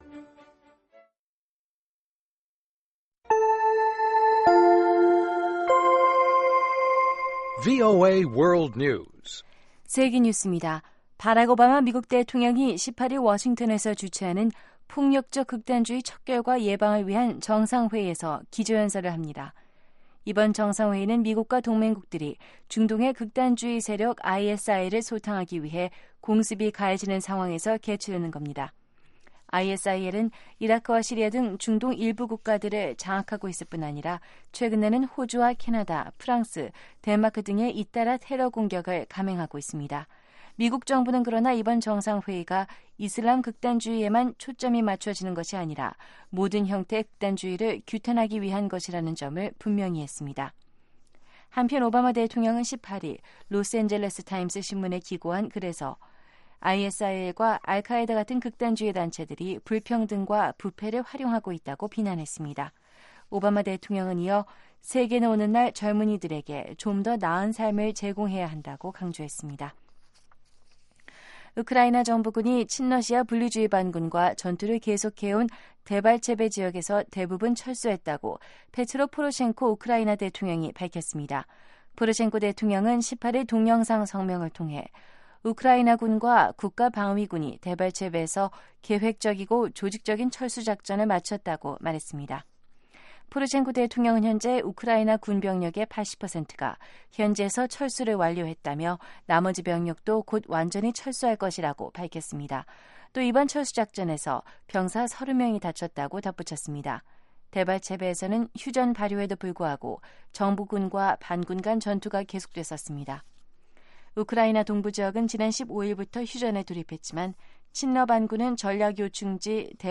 VOA 한국어 방송의 시사 교양 프로그램입니다.